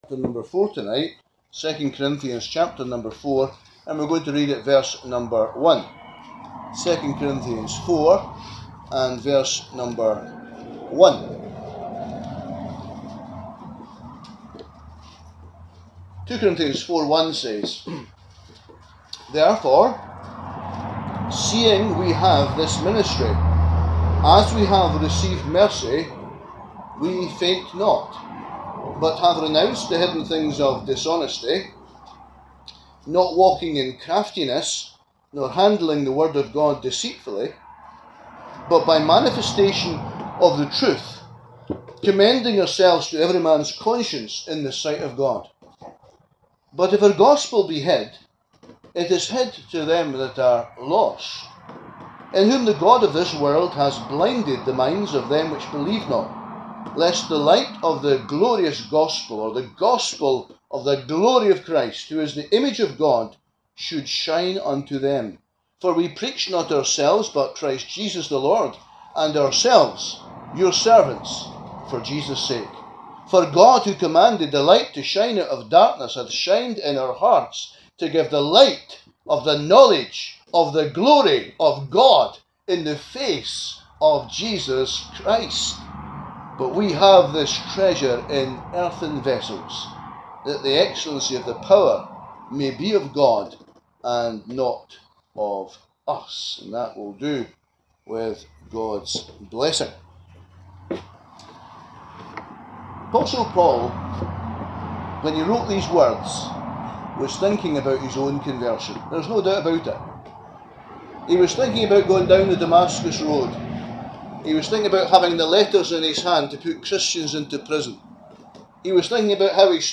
He explains very simply about how you need to recognise you are lost. Once you see your need you are not far from finding the Saviour as your Lord.